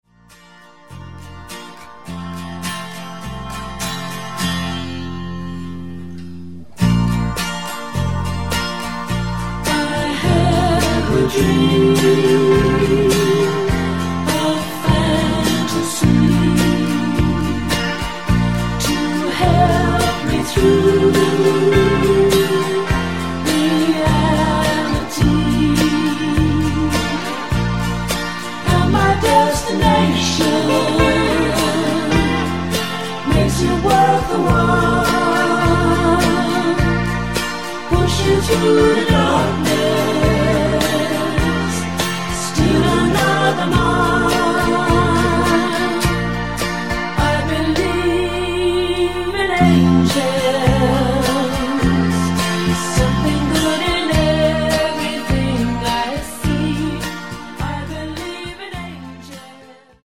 코러스 MR 입니다.
앞부분30초, 뒷부분30초씩 편집해서 올려 드리고 있습니다.
중간에 음이 끈어지고 다시 나오는 이유는